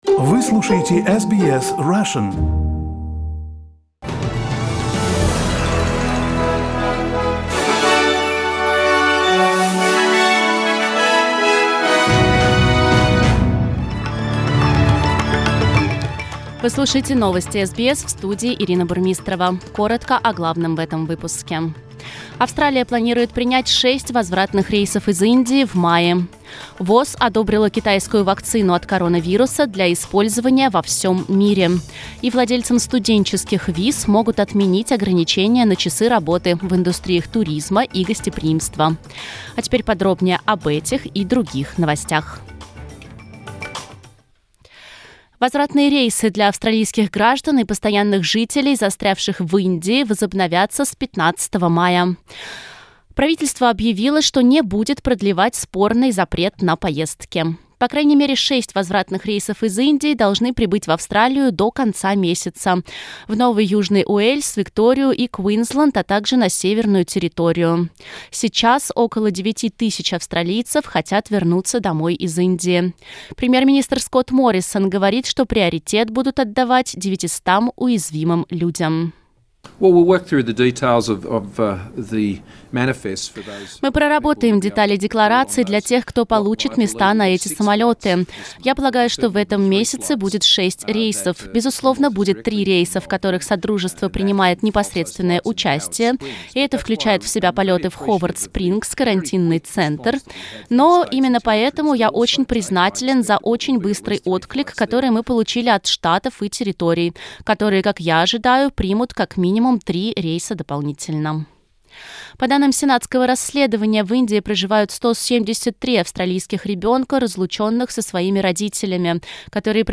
Новости SBS на русском языке - 8.05